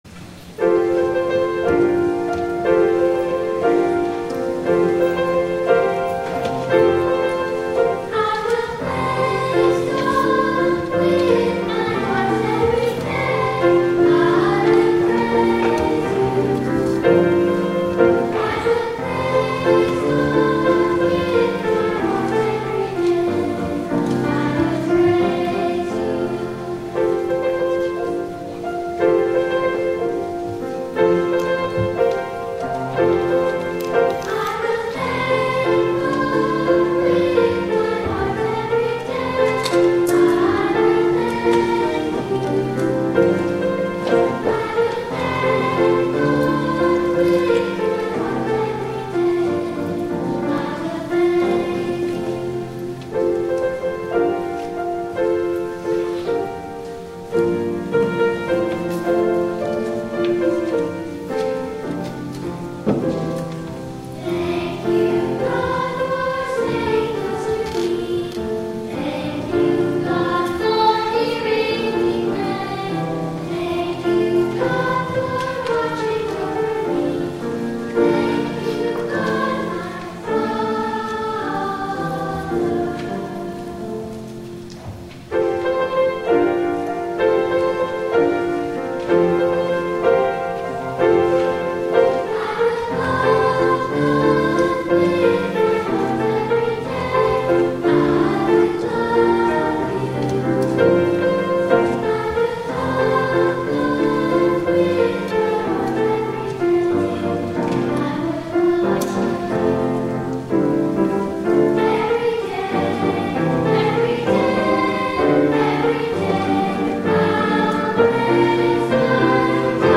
Treble Choir